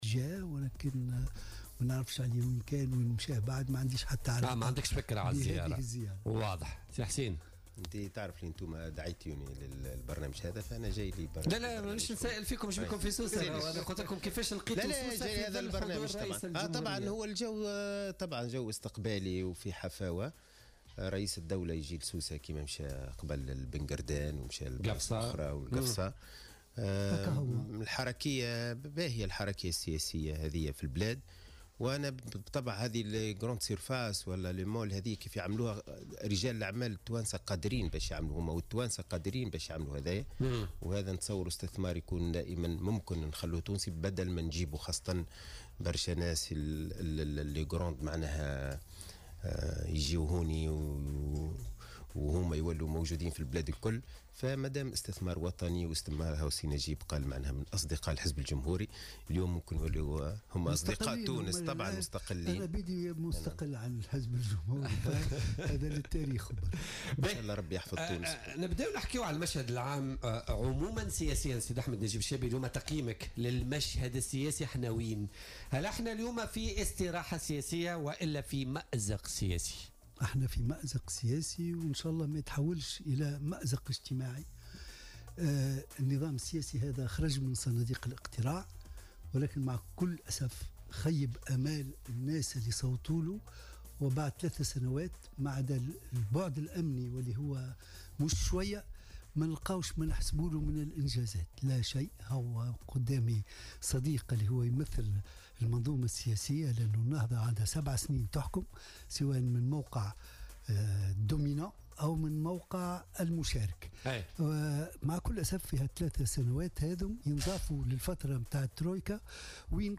أكد القيادي السابق في الحزب الجمهوري أحمد نجيب الشابي ضيف بولتيكا اليوم 4 أكتوبر 2017 أن تونس تعاني اليوم مأزقا سياسيا معربا عن أمله في ألا يتحول إلى مأزق اجتماعي على حد قوله.